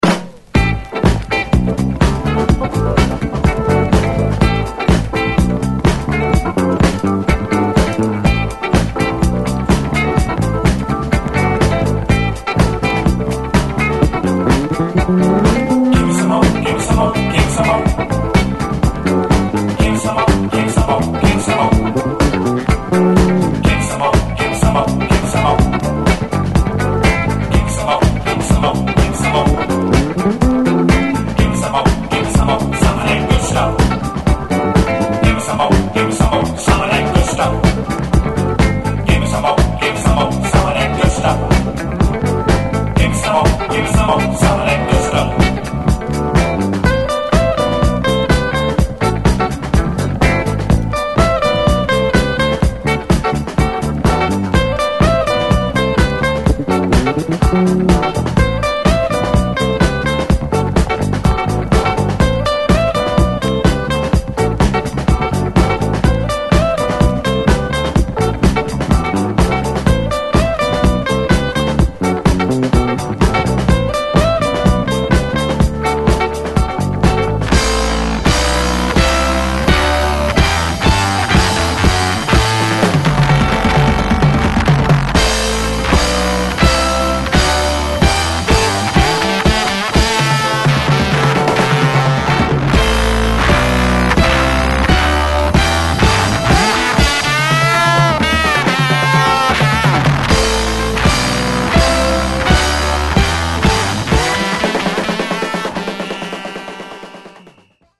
Funk Classic！